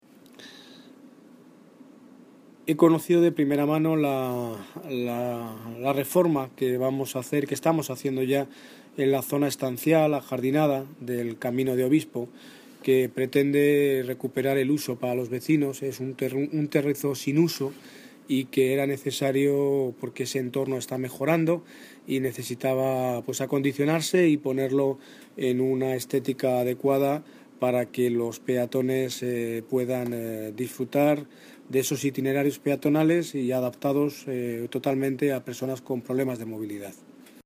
Audio - Daniel Ortiz (Alcalde de Móstoles) Sobre Camino Obispo